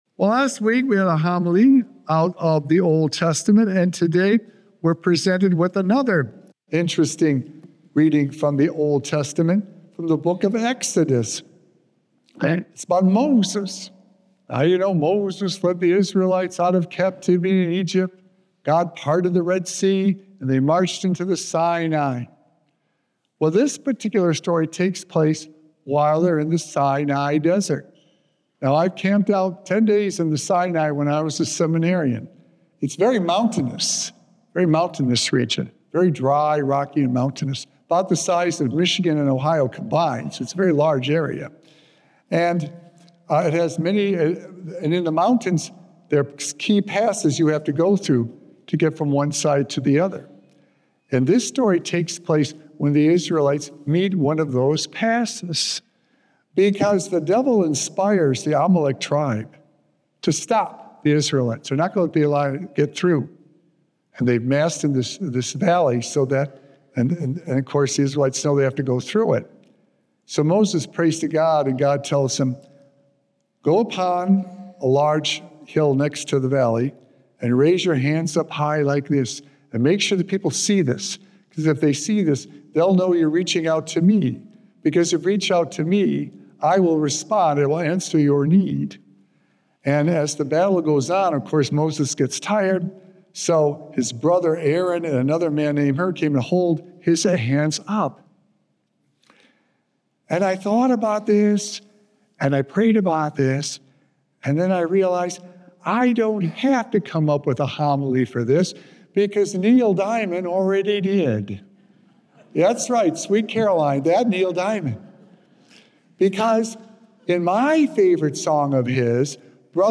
Sacred Echoes - Weekly Homilies Revealed
Like the Neil Diamond song, Brother Love’s Traveling Salvation Show, we know that reaching out to those in need will earn us a spot in the Heavenly Kingdom! Recorded Live on Sunday, October 19th, 2025 at St. Malachy Catholic Church.